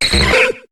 Cri de Métamorph dans Pokémon HOME.